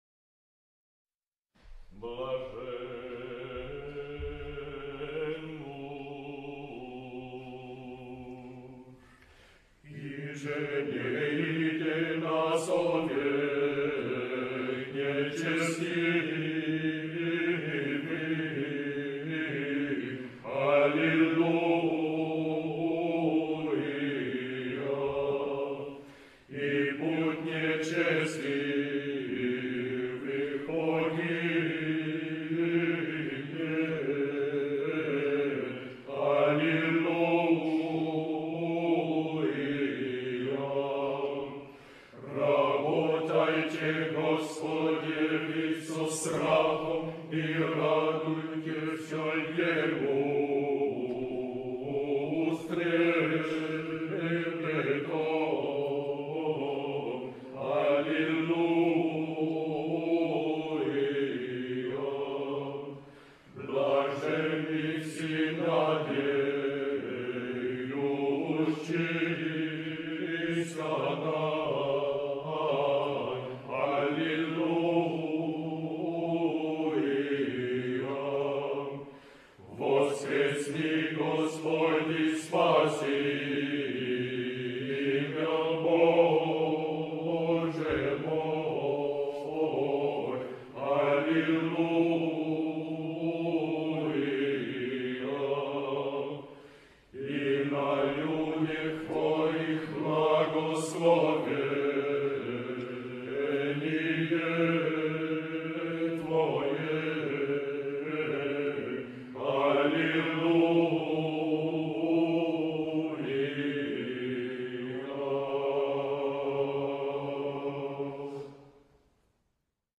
Programme of the March 11th concert 2001
Choir of the Russian Church